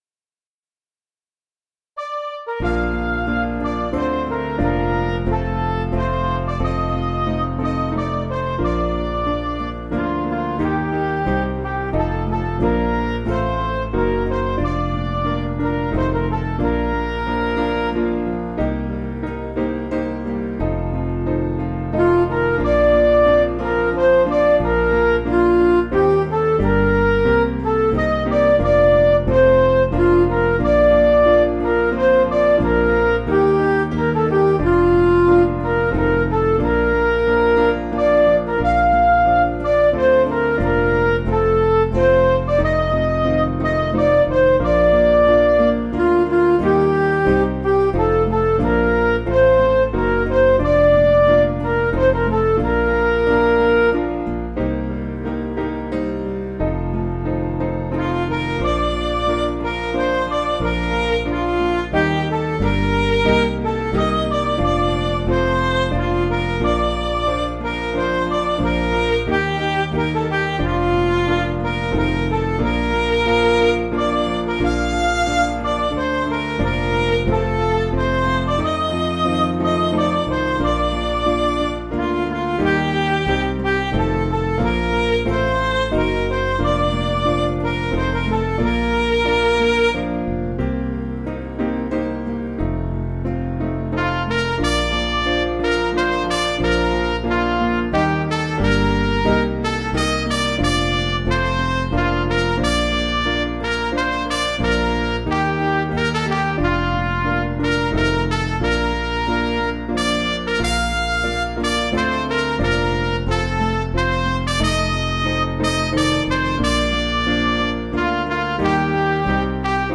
Piano & Instrumental
(CM)   3/Bb
Midi